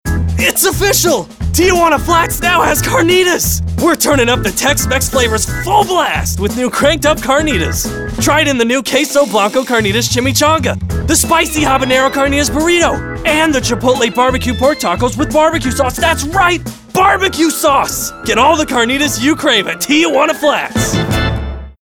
A bright male voice, with amazing range!
announcer, high-energy, kooky, oddball, perky, promo, upbeat